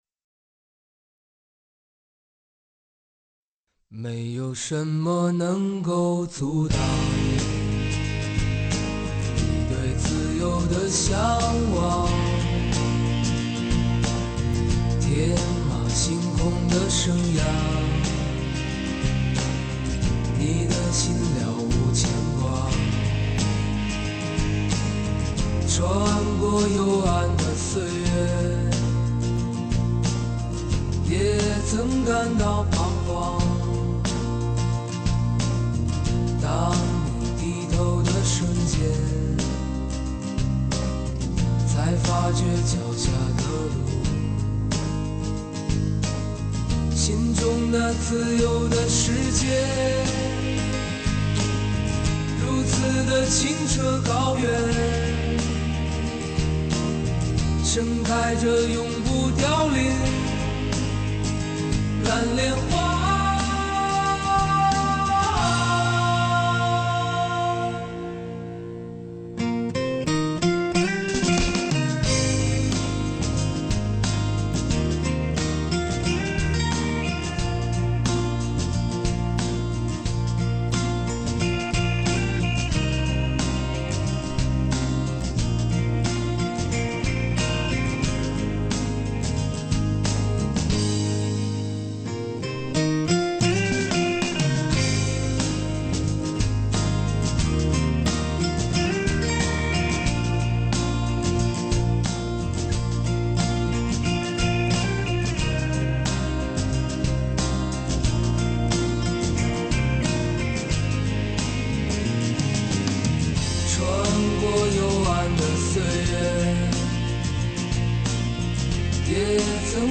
华语摇滚乐坛中坚力量再掀摇滚风暴